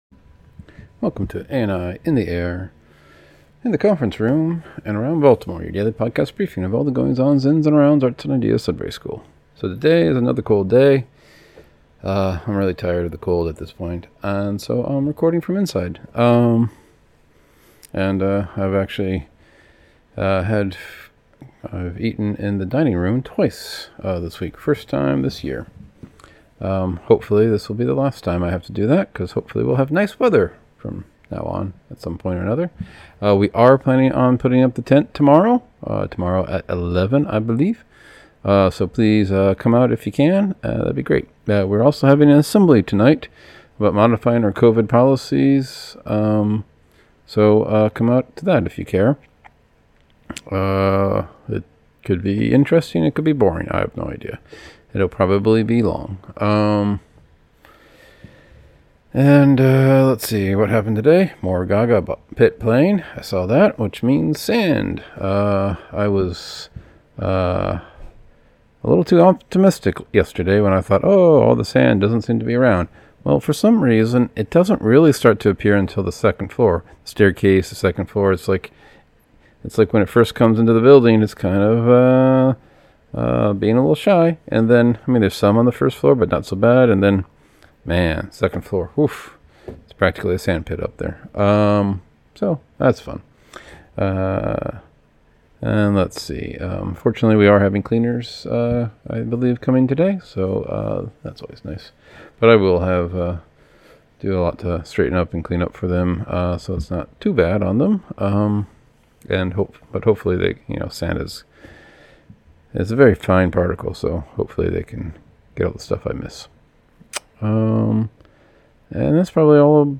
Another cold day, recording inside.